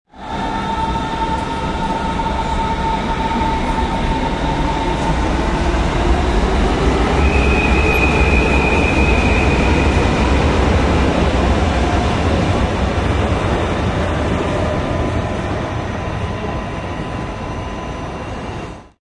城市 " 火车出发
描述：柏林火车站
Tag: 铁路 车站 现场记录 火车